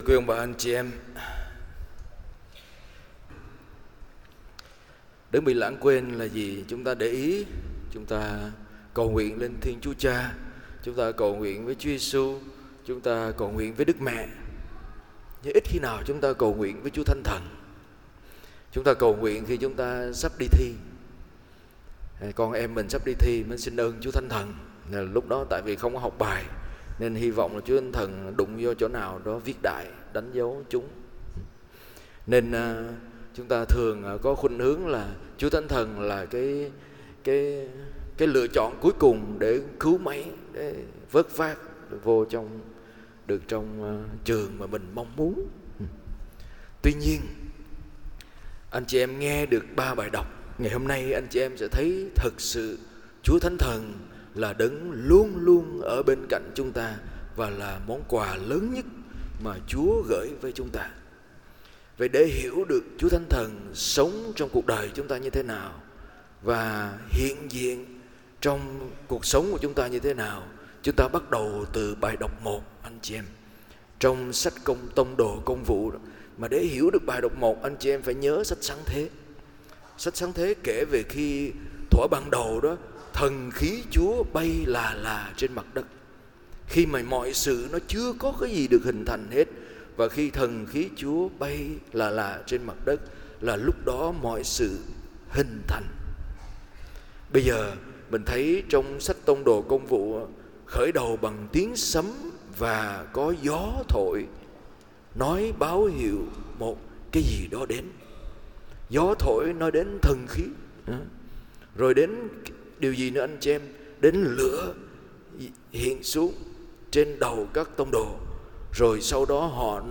Bài giảng Phúc Âm